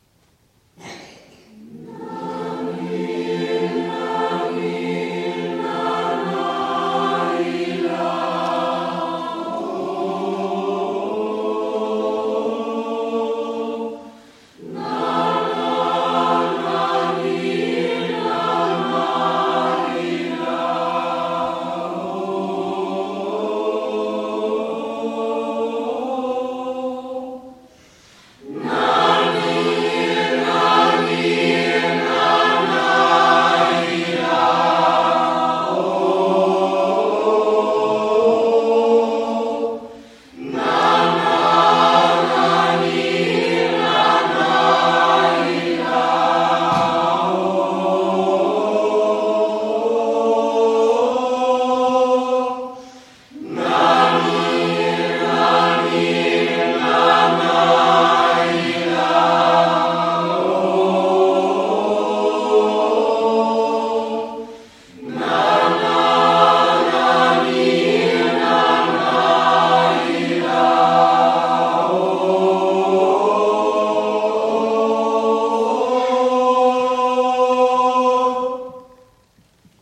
Winterkonzert Chorino und Choralle
MP3: Nanil Nana (georgisches Wiegenlied)
zum Nachhören, am besten mit Kopfhörern